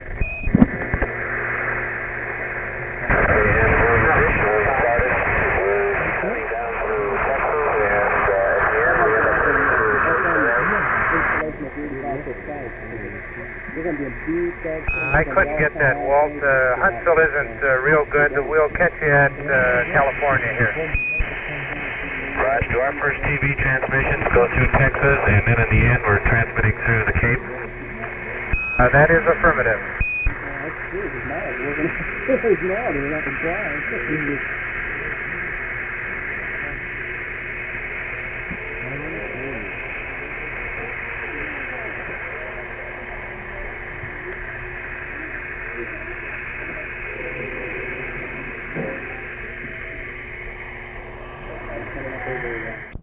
Poor quality HF link to Huntsville.
Capcom is Jack Swigert.
Battery performance on compact cassette recorders was notorously bad.
As the recording progresses, the battery levels drop, the tape slows, and at playback the pitch goes up.
Quindar tones were used as references to correct the speed.)